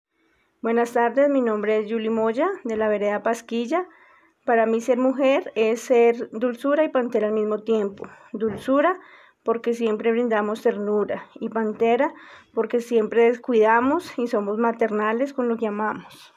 Narrativas sonoras de mujeres